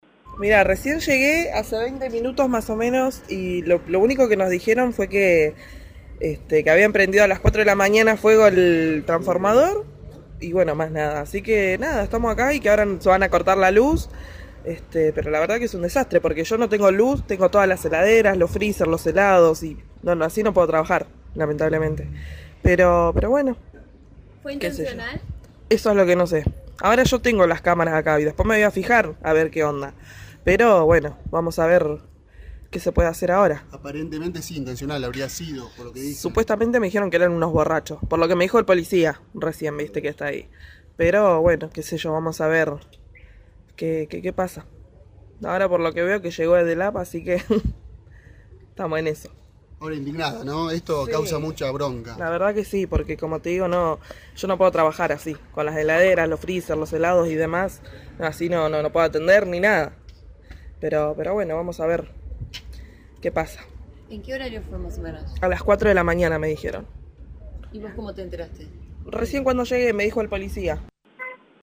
"Por lo que me dijo el policía que está ahí, fue un grupo de borrachos", declaró la mujer en diálogo con La Redonda.